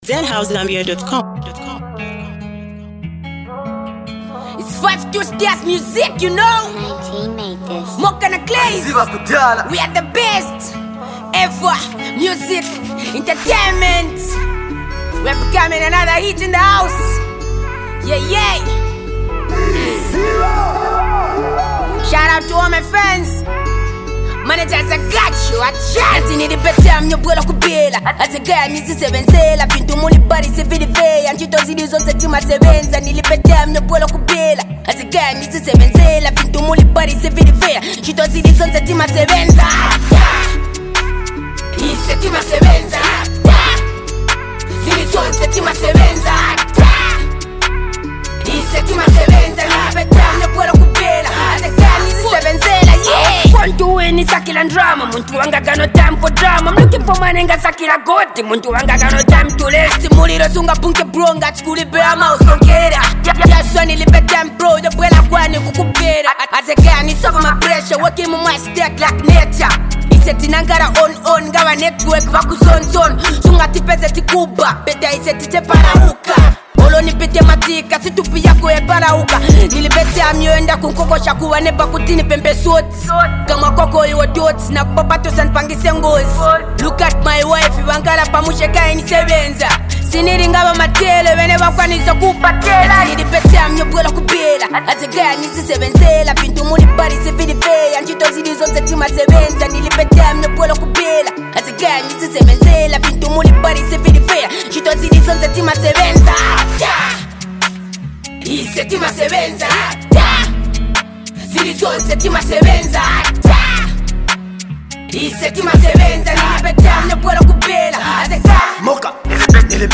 a motivational banger